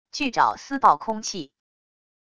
巨爪撕爆空气wav音频